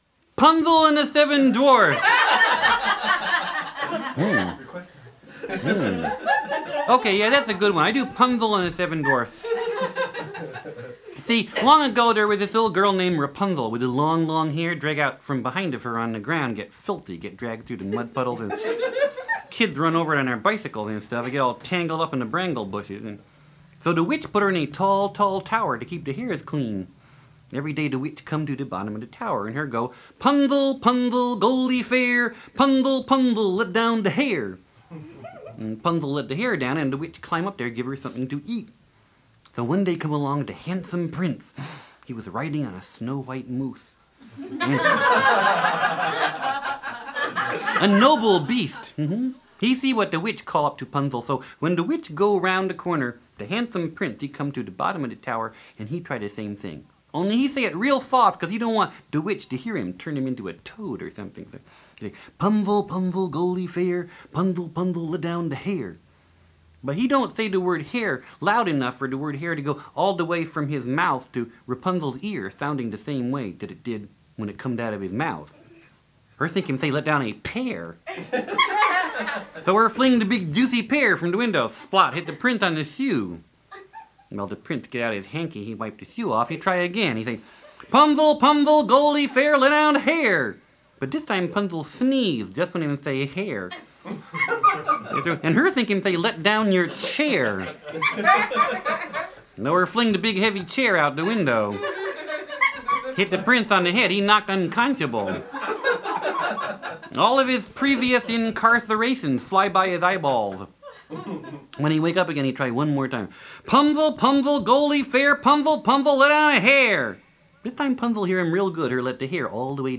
Family storytelling at its most hilarious